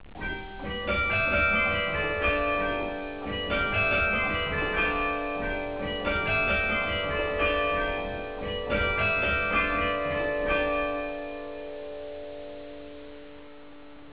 Le carillon du beffroi de Bergues - Les Ritournelles - 1er Quart